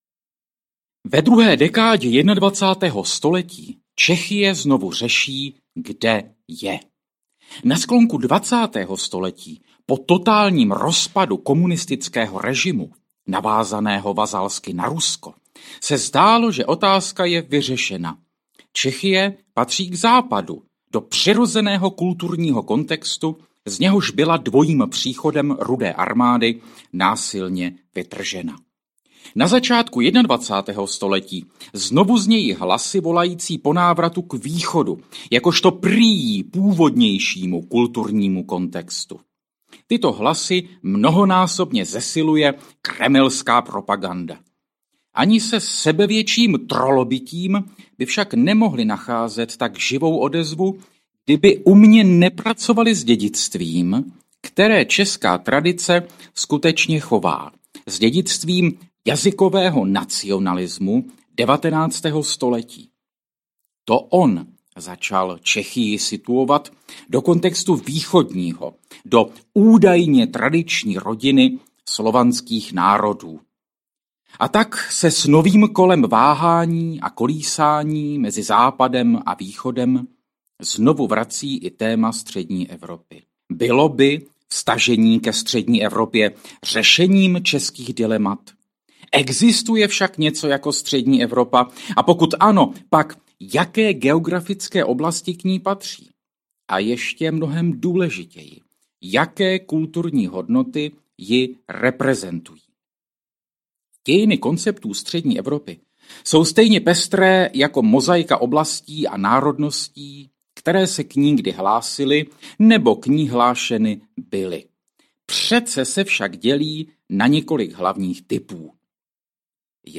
Obrazy z kulturních dějin Střední Evropy audiokniha
Ukázka z knihy
• InterpretMartin C. Putna